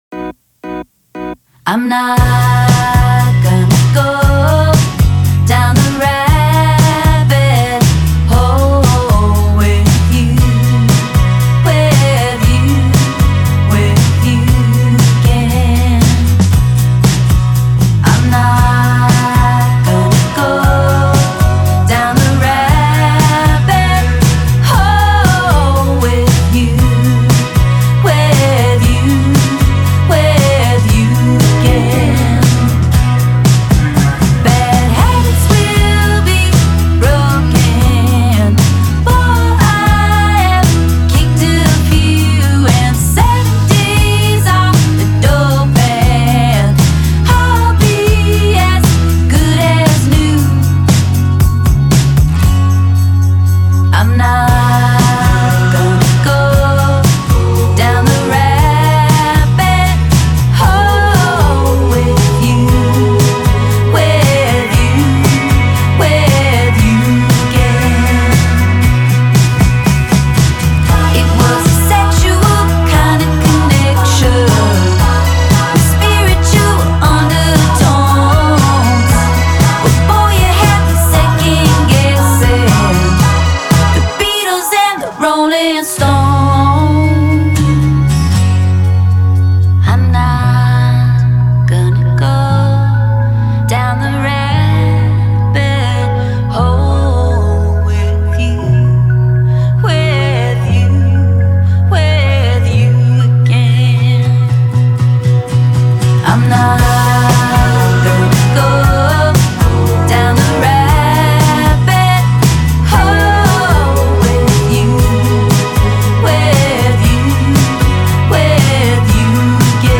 embracing a pop sensibility that channels a fun 1970s swing.